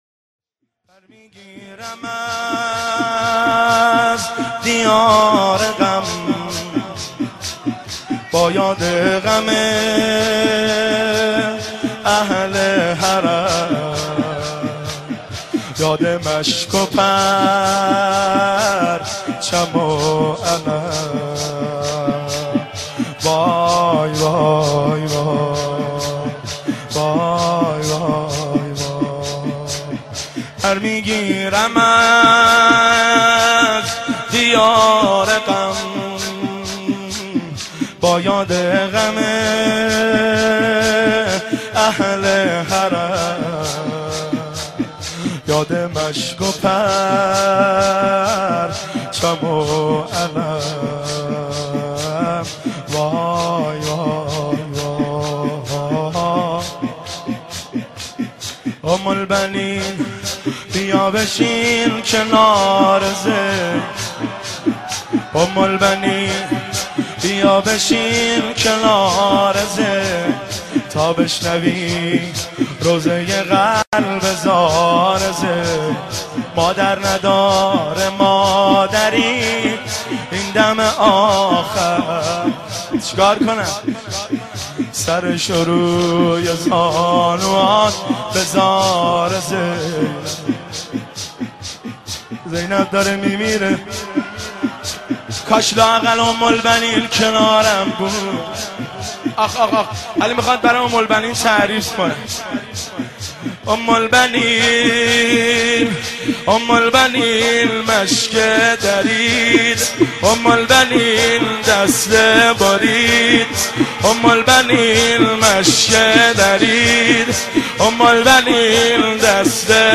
مداحی پر میگیرم از دیار غم(شور) شهادت حضرت زینب(س) 1390 هیئت بین الحرمین
مداحی پر میگیرم از دیار غم(شور)